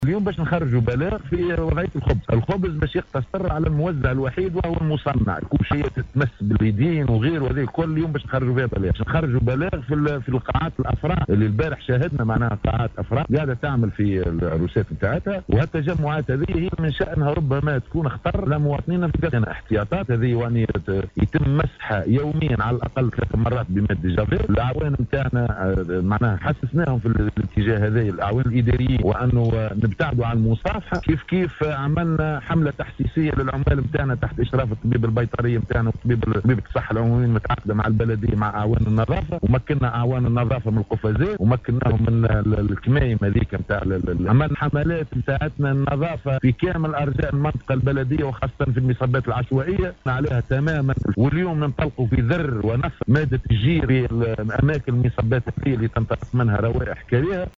أكد رئيس بلدية القصرين محسن مدايني لإذاعة السليوم اف ام انه وتوقيا من انتشار فيروس كورونا المستجد  تم اخذ إجراءات وقائية تهم الطاقم العامل بالبلدية وكذلك المواطنون من خلال انه سيتم اليوم  إصدار بلاغ بخصوص منع بيع الخبز بالمحلات التجارية المفتوحة بكامل الجهة والاقتصار فقط على المخابز التي ستكون المزود الوحيد لهذا المتوج بالإضافة  إلى النظر في حفلات الزفاف التي ستكون مصدرا لانتشار كورونا بسبب التجمعات وفق تعبيره .